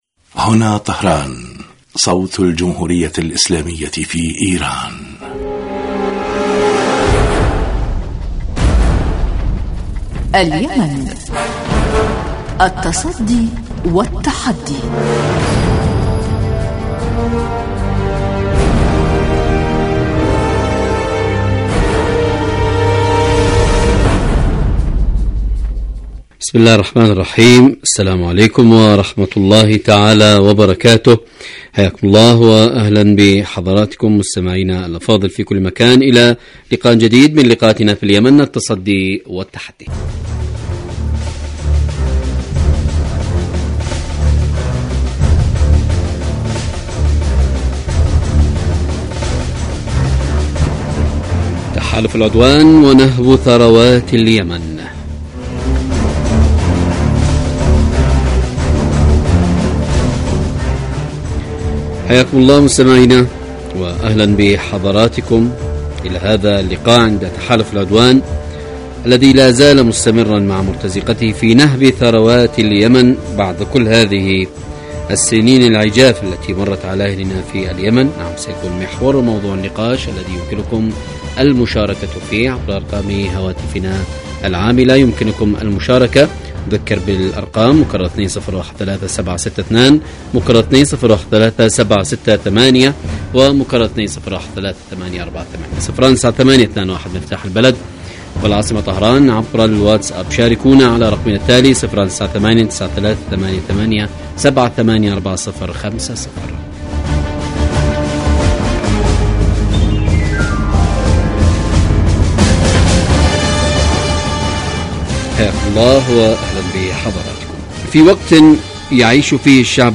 برنامج سياسي حواري يأتيكم مساء كل يوم من إذاعة طهران صوت الجمهورية الإسلامية في ايران
البرنامج يتناول بالدراسة والتحليل آخر مستجدات العدوان السعودي الأمريكي على الشعب اليمني بحضور محللين و باحثين في الاستوديو.